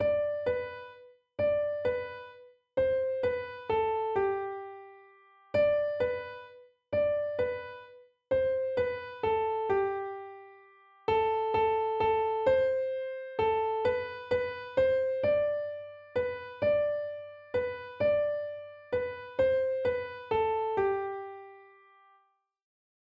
* Teaching the rhythmic element too-oo-oo (dotted minim / dotted half note) * Prepare the melodic element 'fa'.